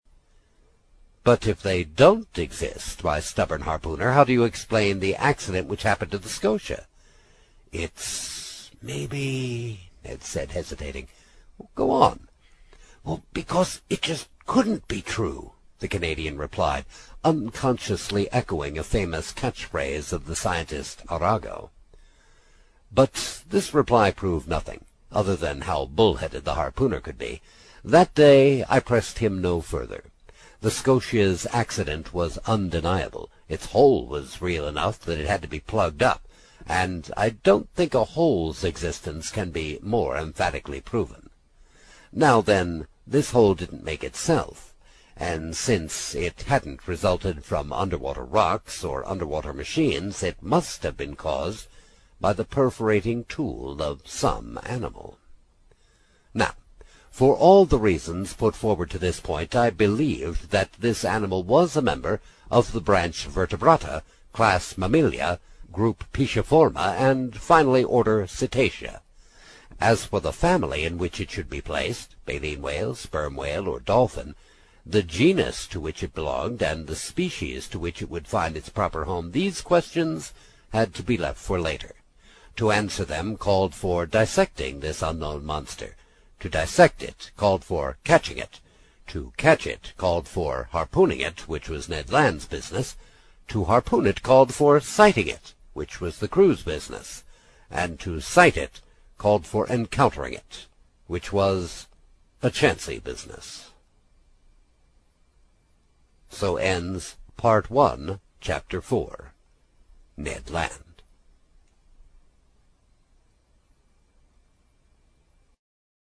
英语听书《海底两万里》第45期 第4章 尼德兰(14) 听力文件下载—在线英语听力室
在线英语听力室英语听书《海底两万里》第45期 第4章 尼德兰(14)的听力文件下载,《海底两万里》中英双语有声读物附MP3下载